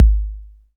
KIK 808 K 6.wav